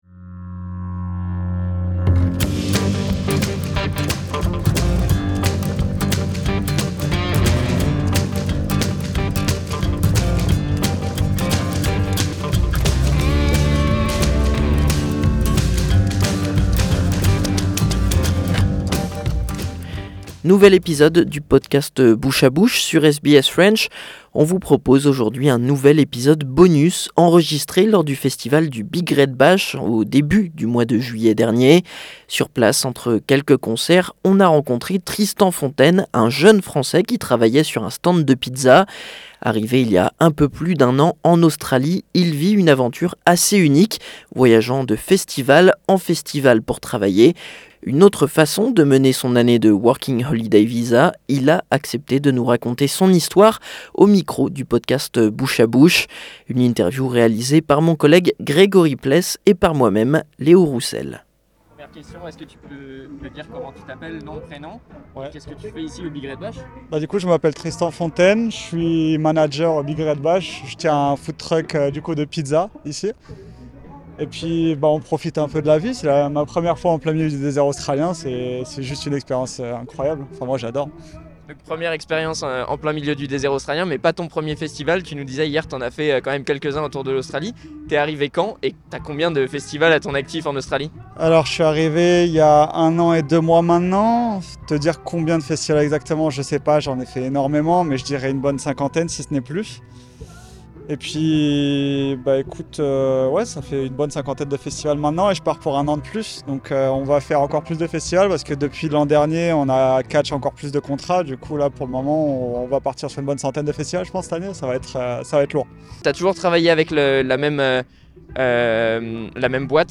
À l'occasion des fêtes de fin d'année, nous vous proposons de redécouvrir cette interview réalisée au festival du Big Red Bash.